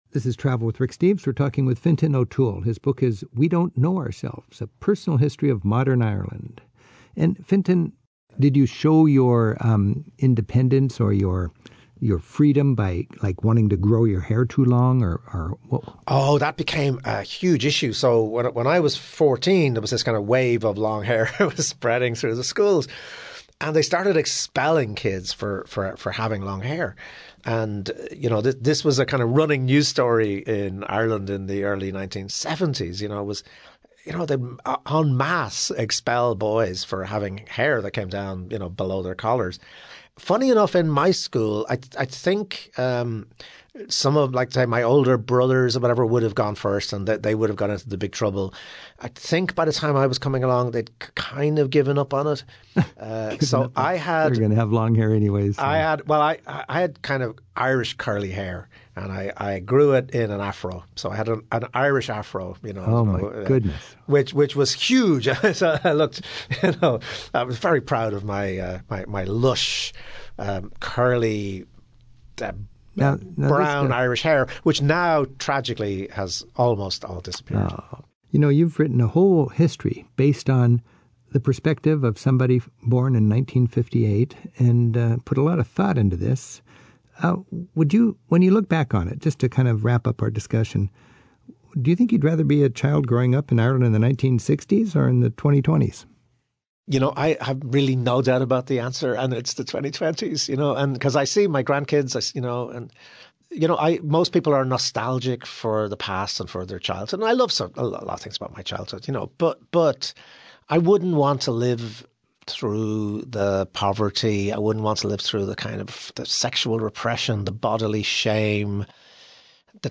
More with Fintan O'Toole - Irish Times columnist Fintan O'Toole tells us what it was like for him as a Dublin teenager in the early 1970s, when men's hairstyles started to get funky.